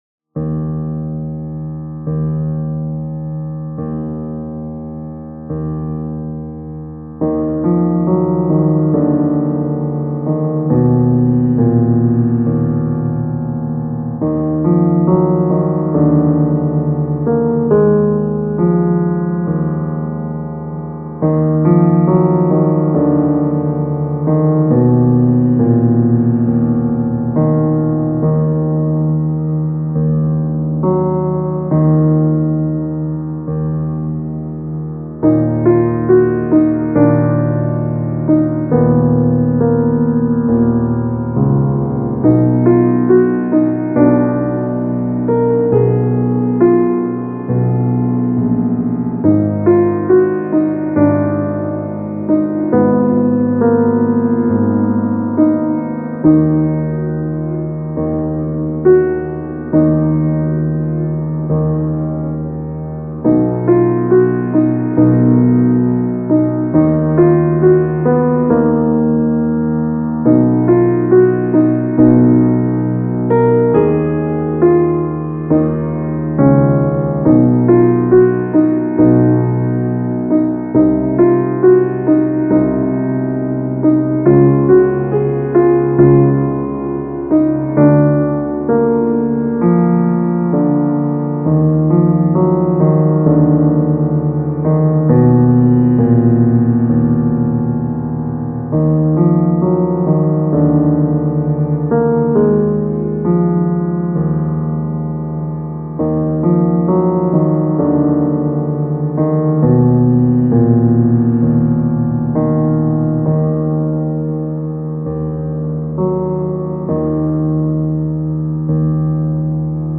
怒りや復讐をイメージした暗く重たいピアノソロ曲です。
テンポ ♩=free